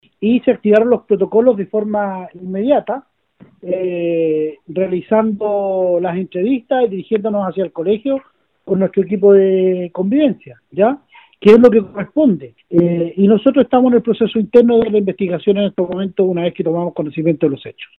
En esa línea, el alcalde, Hans Gonzalez, informó que se inició una investigación para esclarecer lo ocurrido.